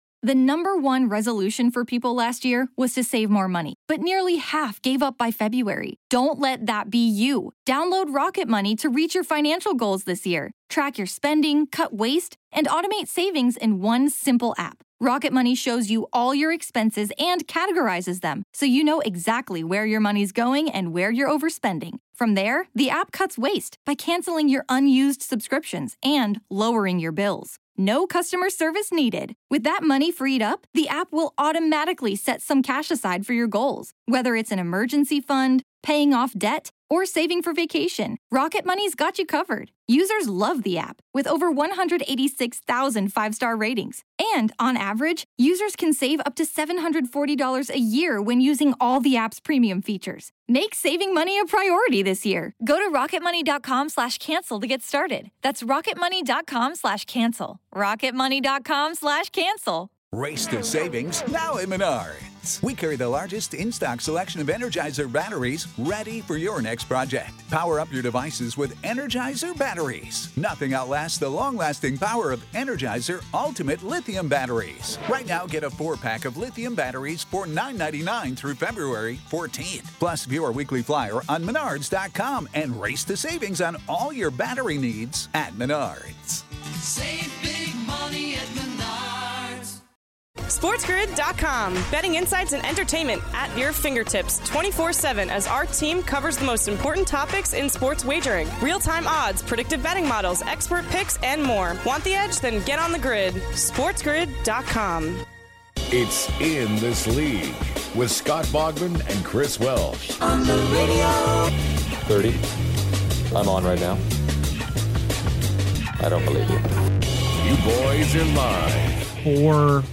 go live on twitch to continue to break down week 12 of the MLB